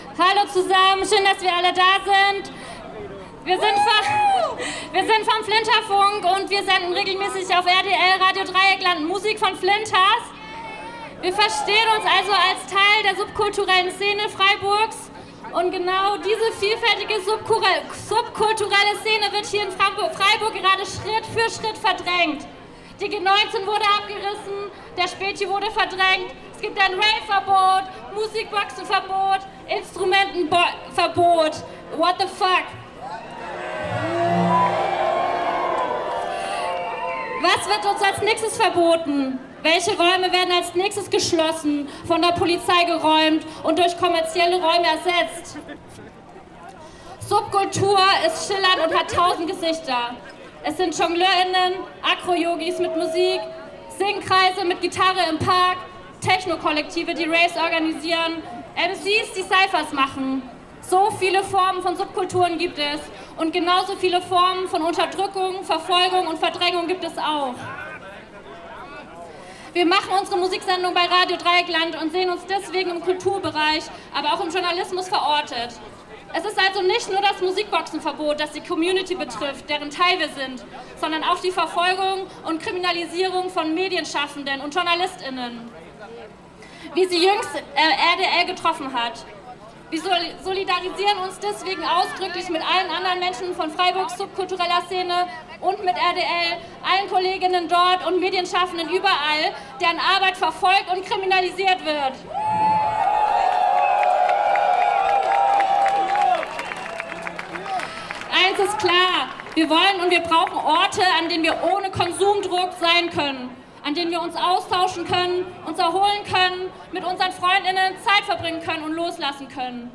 Reportage in Klängen und (O-)Tönen: Trotz Verbot: Über 3500 bei Tanzdemo für subkulturelle Freiräume in Freiburg